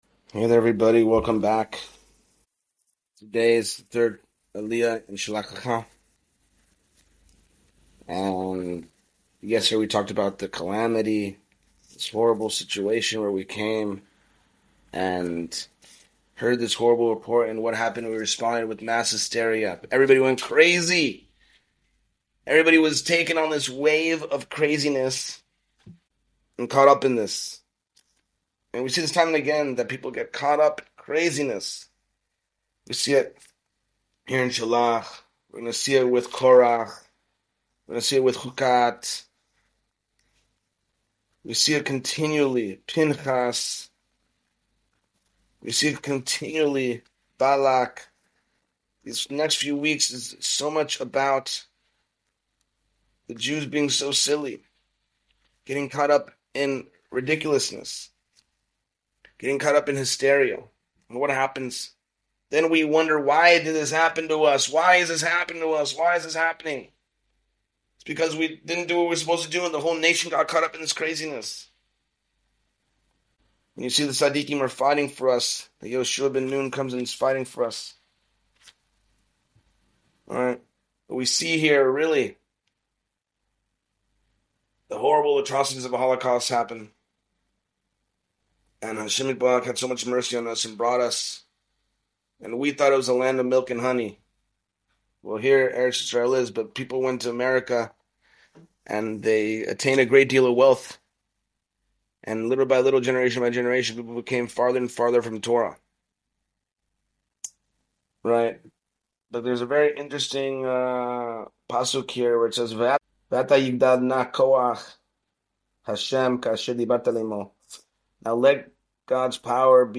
Class Description: